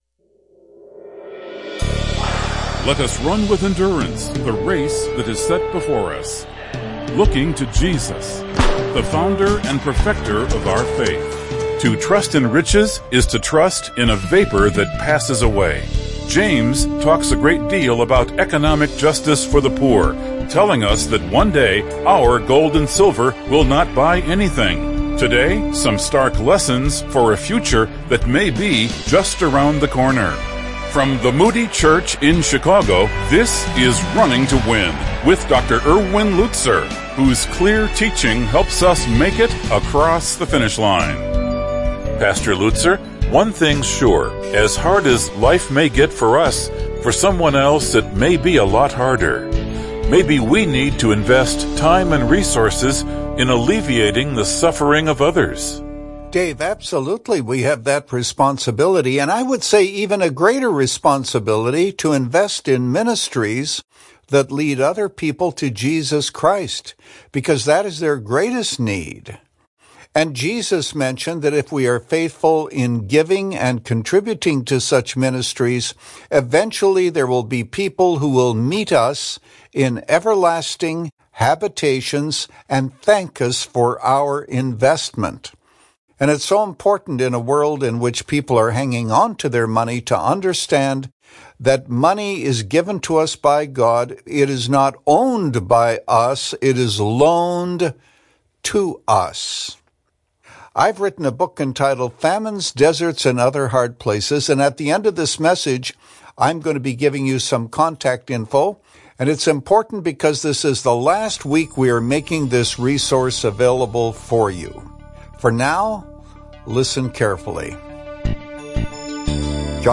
In this message from the book of James, Pastor Lutzer identifies key lessons about wealth, knowing the Judge stands at the door.